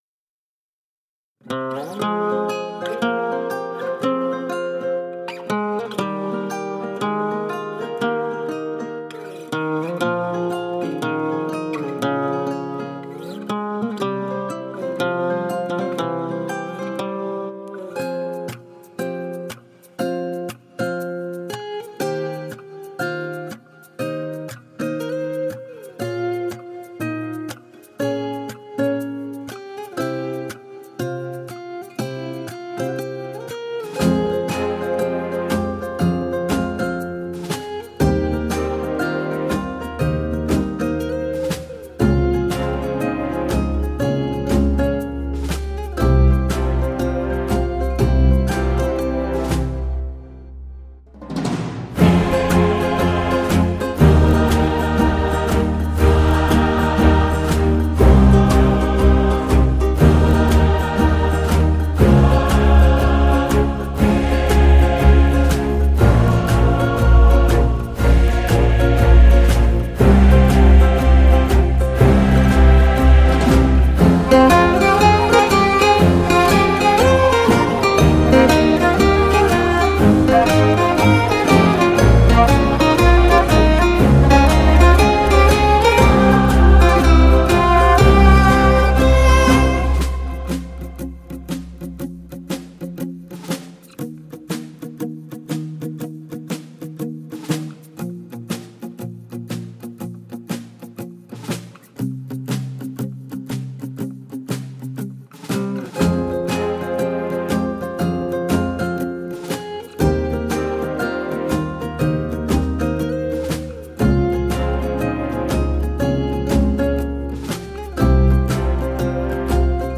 اینجا و بی‌کلام